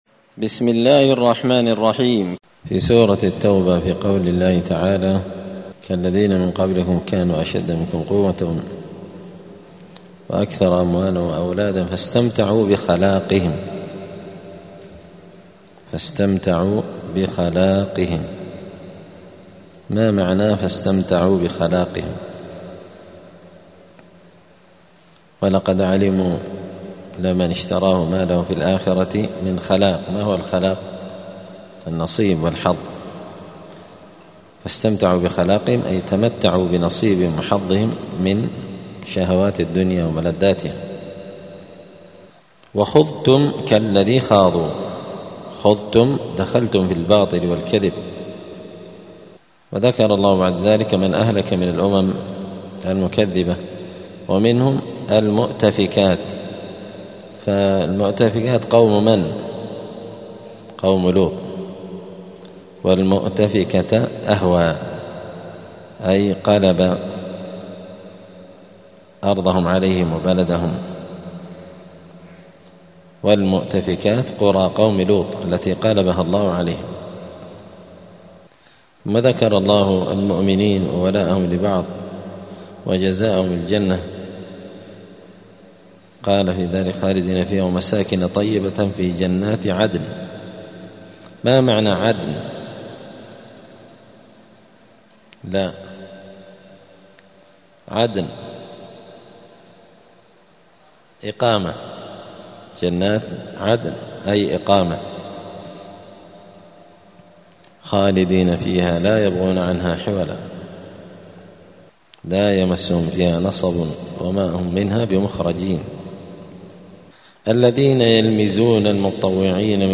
مذاكرة لغريب القرآن في رمضان – الدرس العاشر (10) : غريب الجزء الحادي عشر.
دار الحديث السلفية بمسجد الفرقان قشن المهرة اليمن